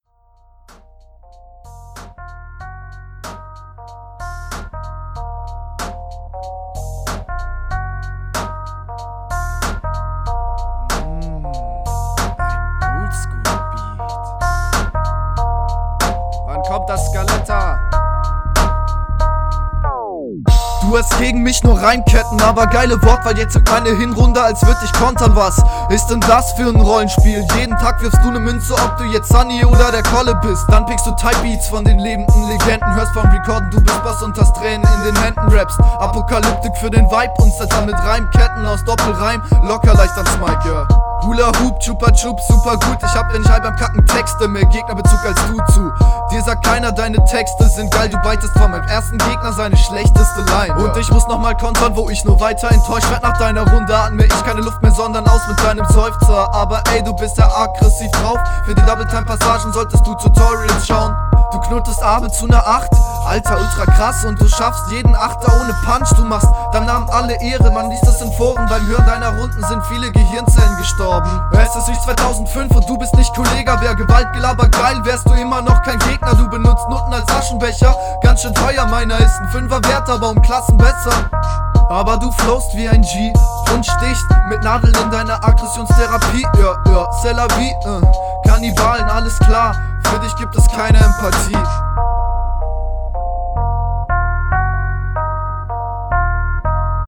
Rappst auch sehr fresh auf dem Beat, find dich hier sogar noch einmal um eine …